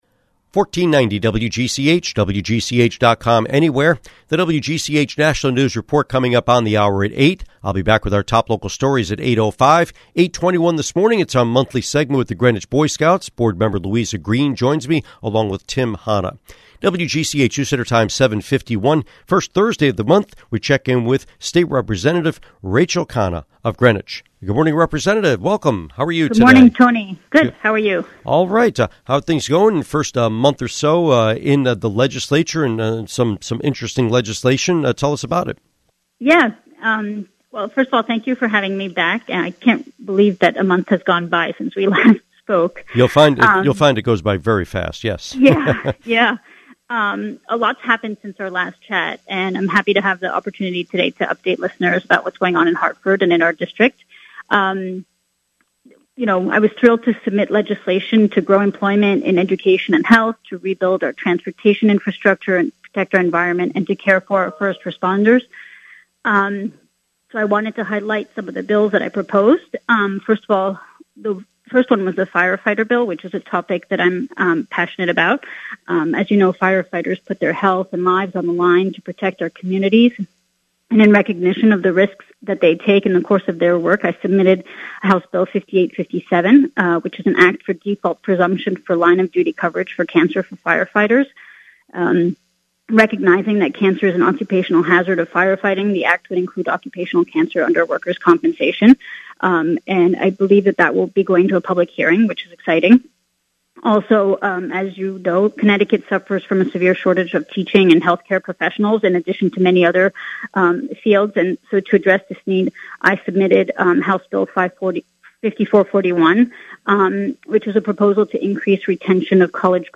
Interview with State Representative Rachel Khanna